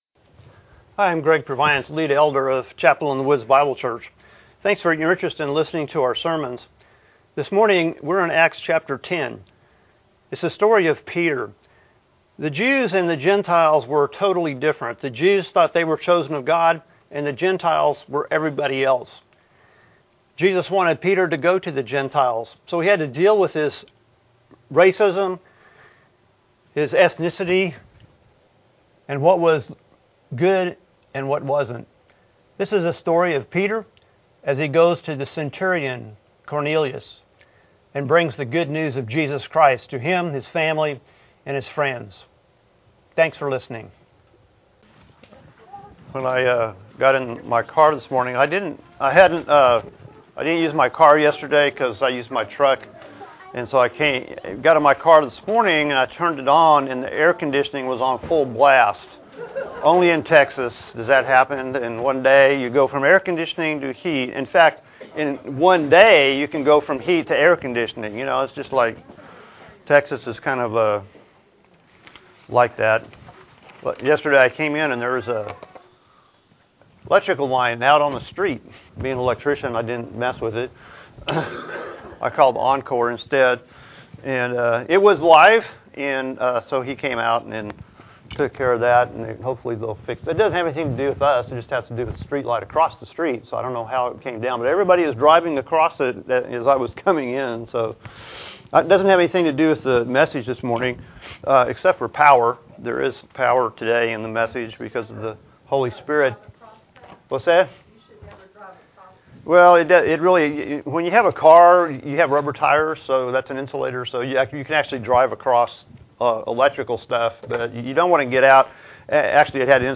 Apr 08, 2018 Acts 10 Peter visits Cornelius MP3 SUBSCRIBE on iTunes(Podcast) Notes Discussion Sermons in this Series Loading Discusson...